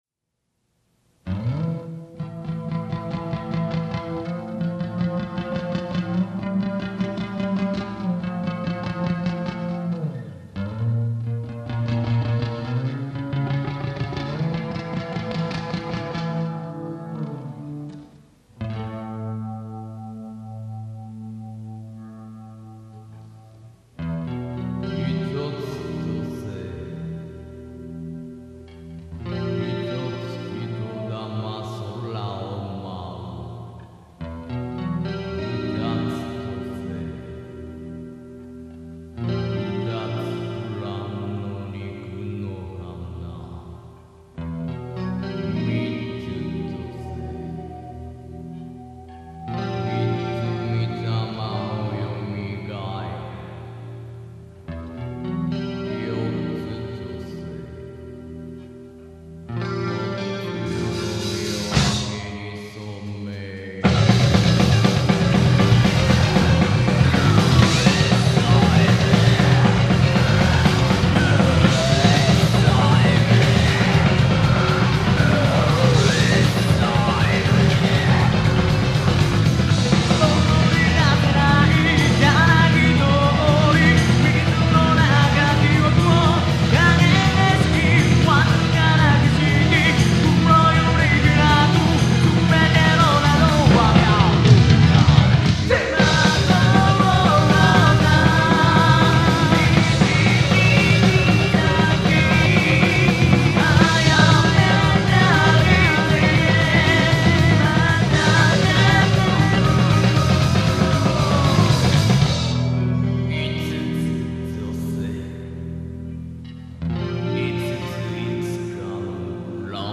＊ボリューム注意